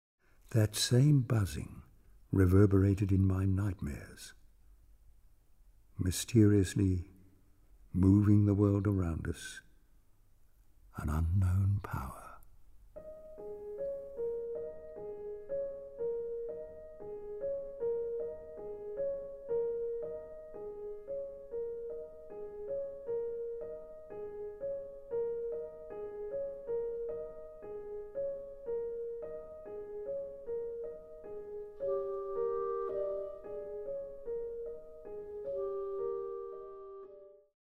richly expressive woodwind palette